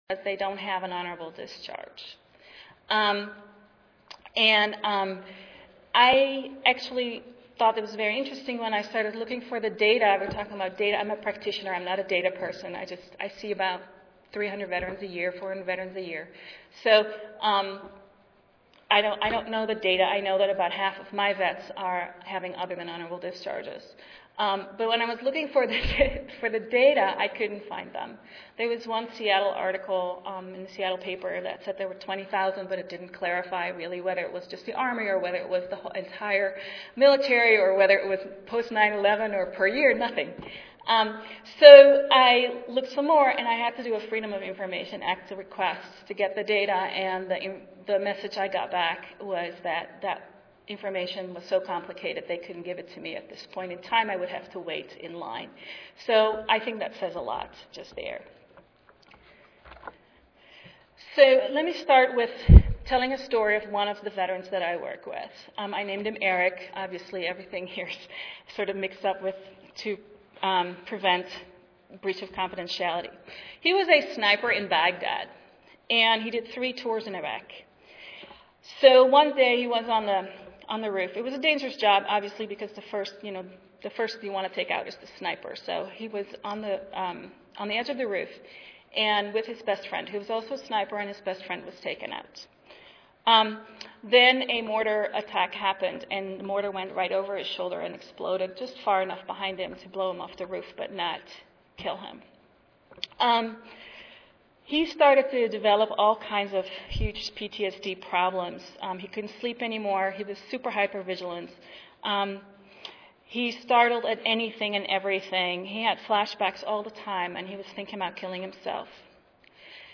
3053.0 Invited Session: Health Care for Recent Veterans Monday, October 29, 2012: 8:30 AM - 10:00 AM Oral This session will discuss disparities in access to health care and disparities in quality of healthcare among veterans of the Iraq and Afghanistan wars.